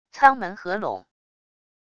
舱门合拢wav音频